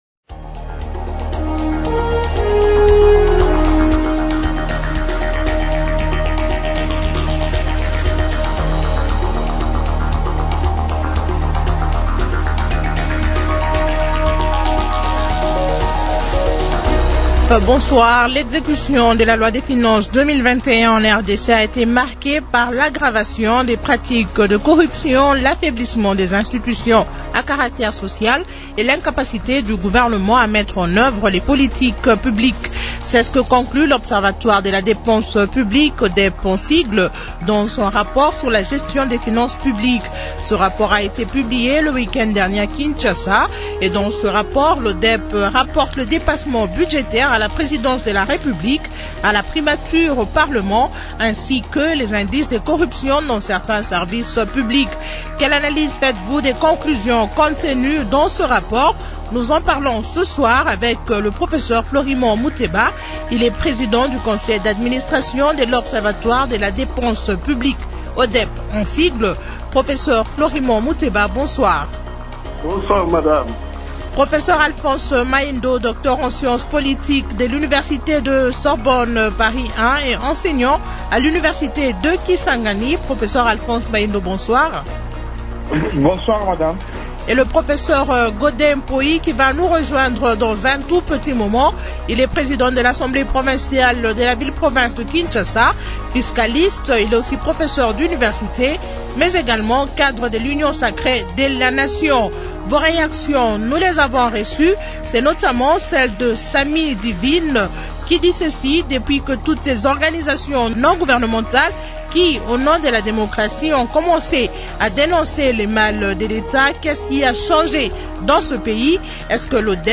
Fiscaliste, il est professeur d’Universités.